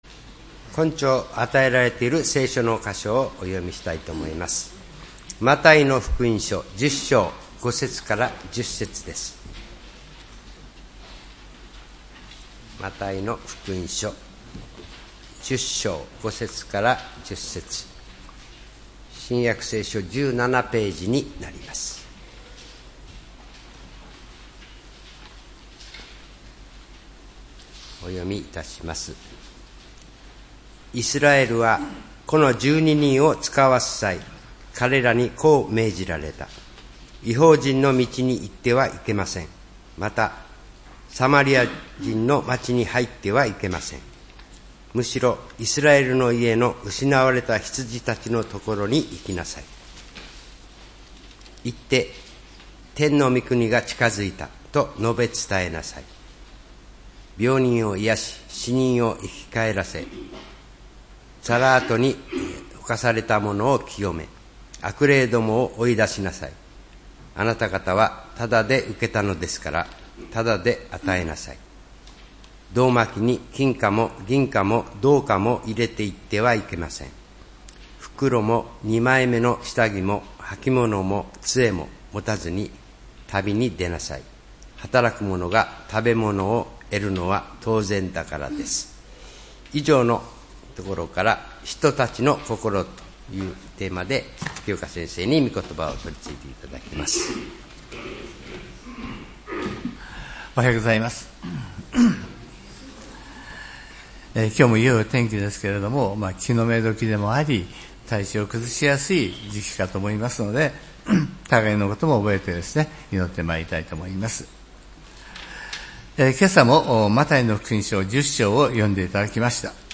礼拝メッセージ「使徒たちの心」（３月１日）